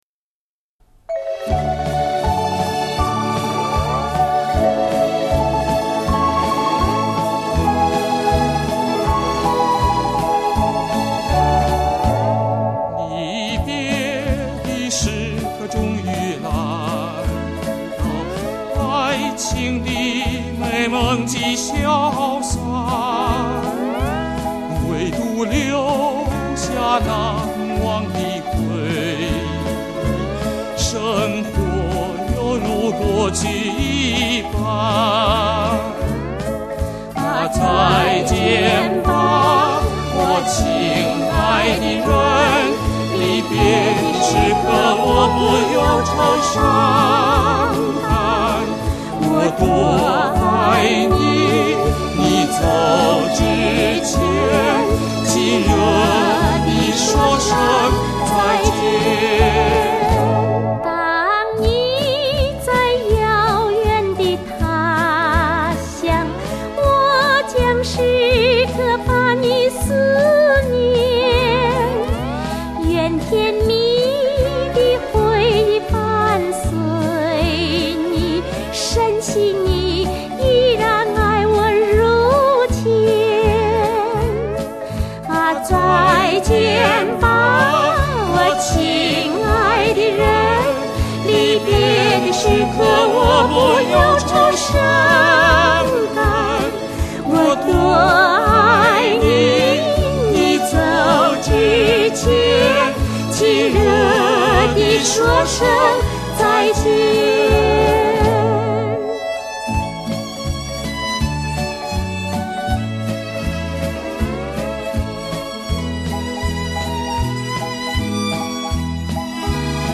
采用美国SRS3D音响技术及24bit数码技术生产的HIFI CD，据称是国内首家采用。
男声